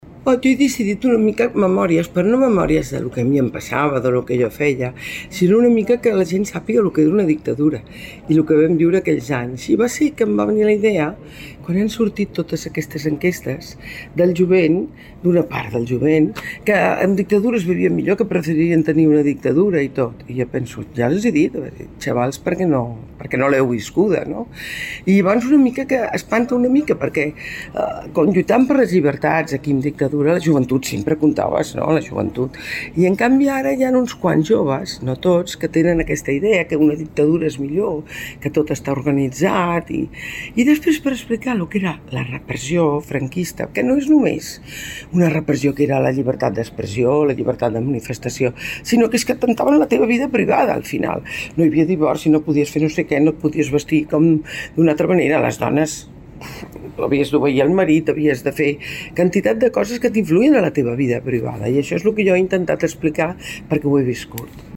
Magda Oranich, advocada i autora 'Totes les batalles'